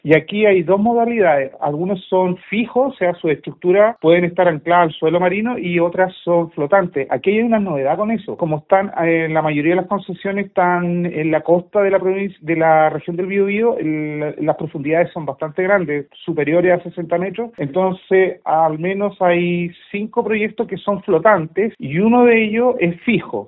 El seremi de Energía de la región, Danilo Ulloa, explicó el tipo de proyectos que están solicitando las concesiones.